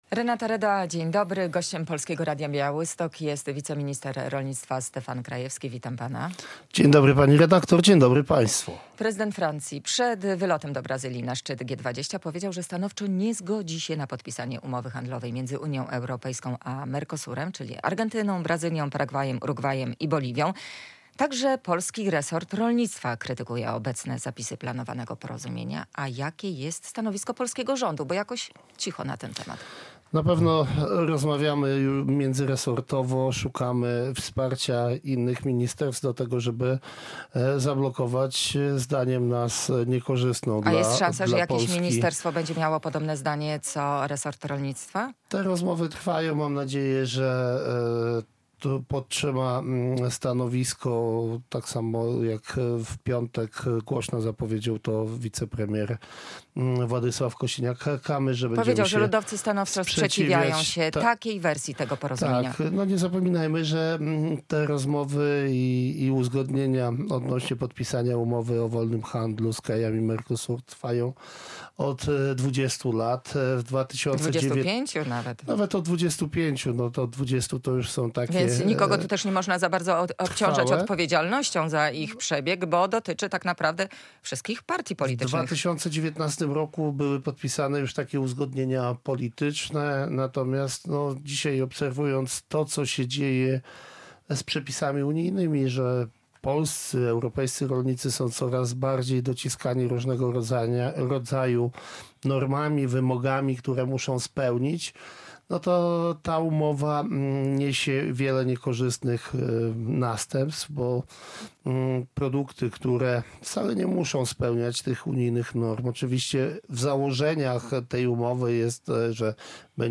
Radio Białystok | Gość | Stefan Krajewski - wiceminister rolnictwa